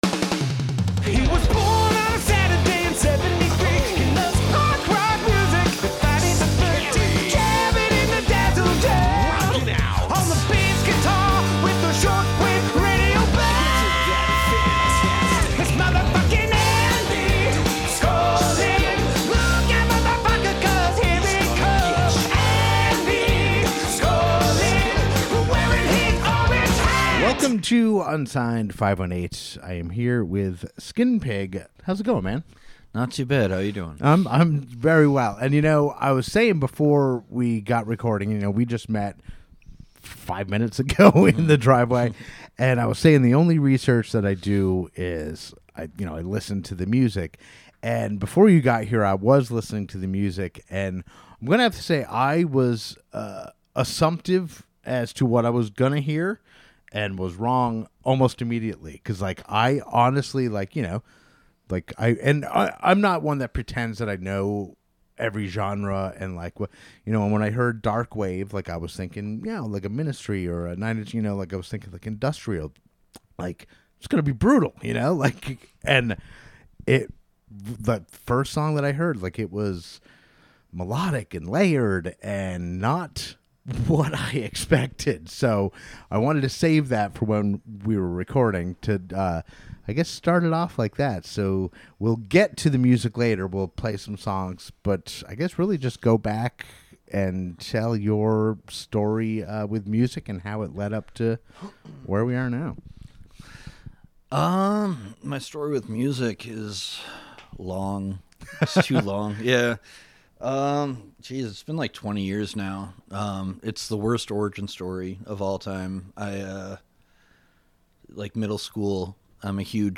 We also play a new single off of his upcoming album.